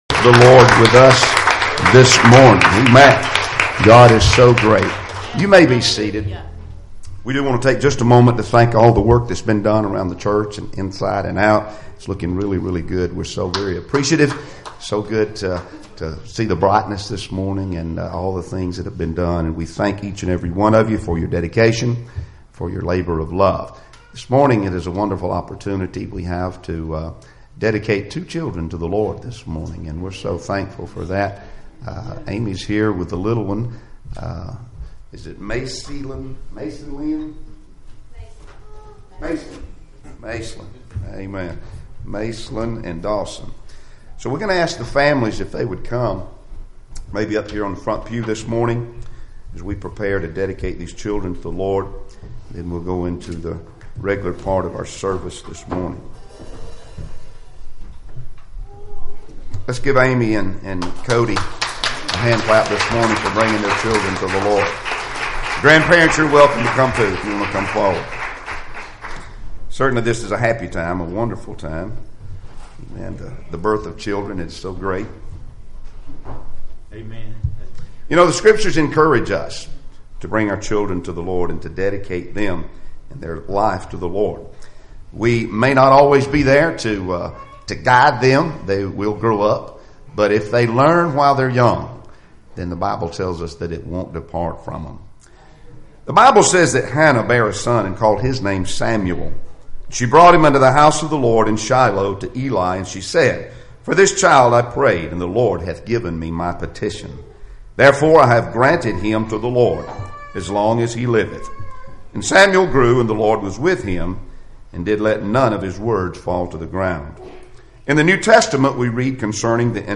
Passage: Matthew 19:16-22 Service Type: Sunday Morning Services Topics